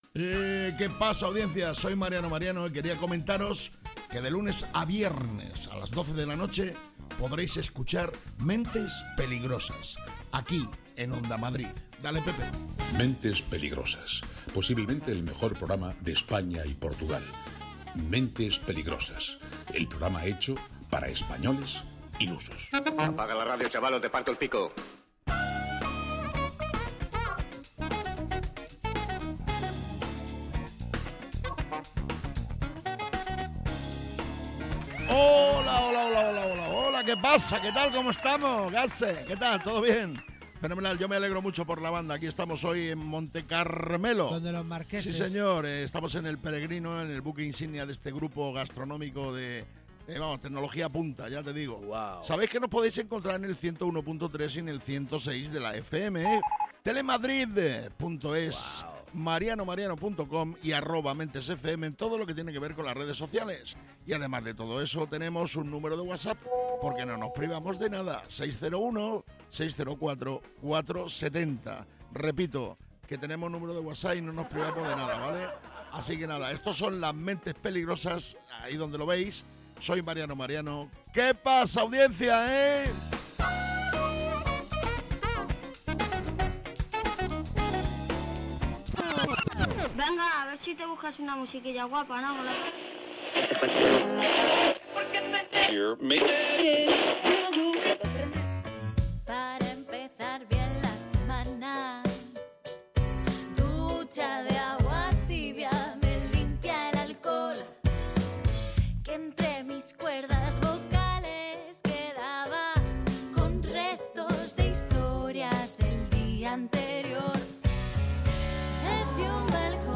¿Es un programa de humor?